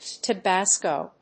音節Ta・bas・co 発音記号・読み方
/təbˈæskoʊ(米国英語), tʌˈbæskəʊ(英国英語)/